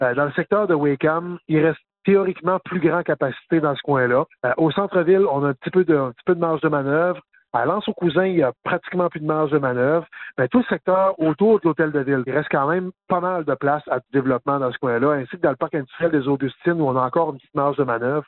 Le maire, Daniel Côté, explique que l’étude dresse un portait assez précis de la situation :